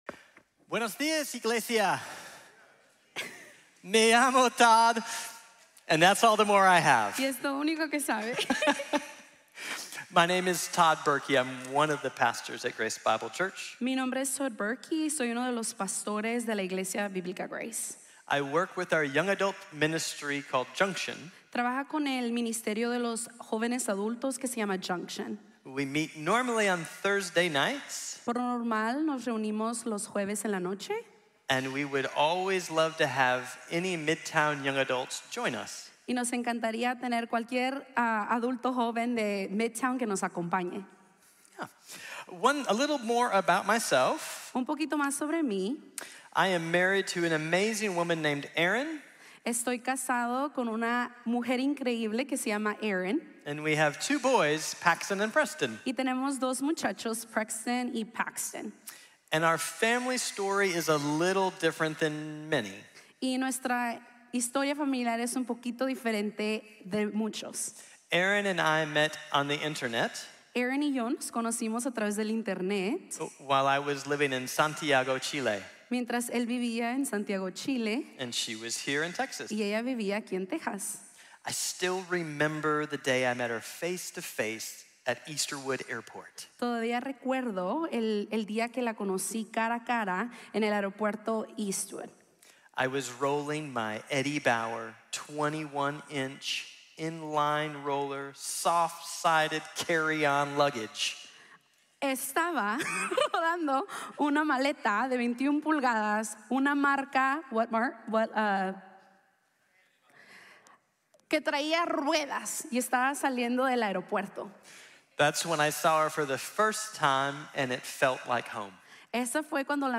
La invitación de las promesas de Dios | Sermon | Grace Bible Church